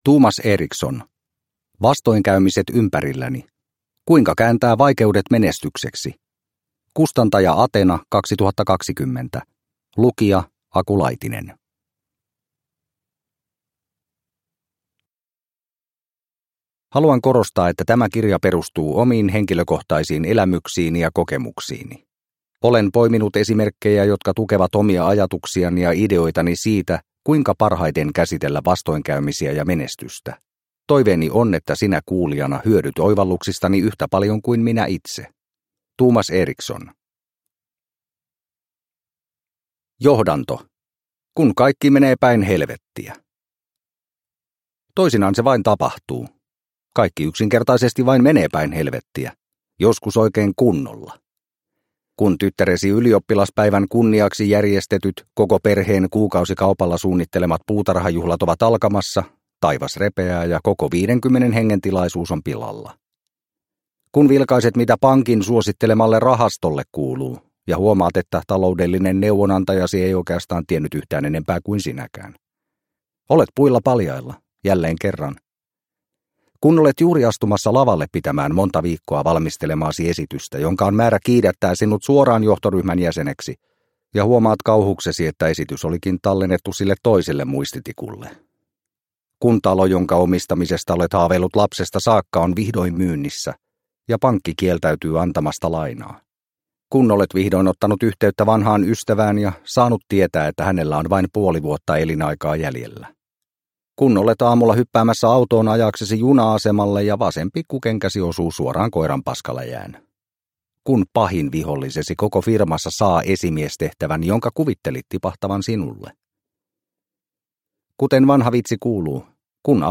Vastoinkäymiset ympärilläni – Ljudbok – Laddas ner